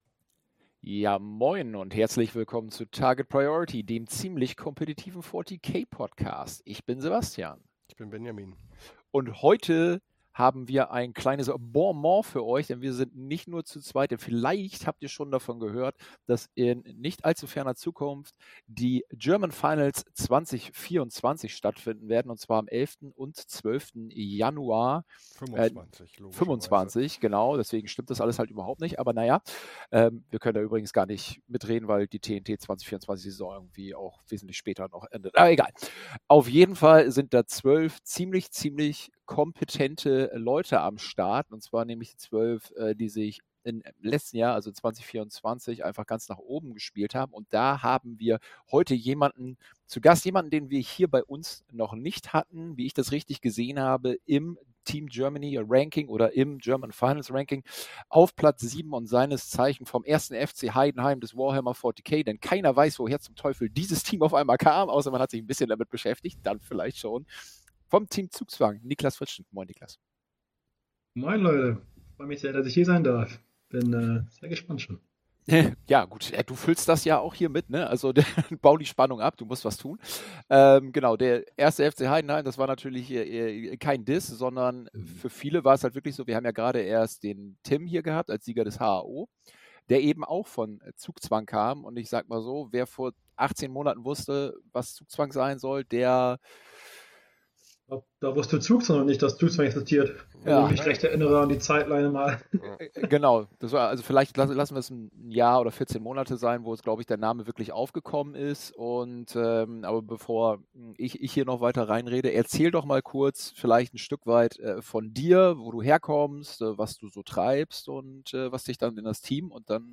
German Finals 2024 - Spielerinterview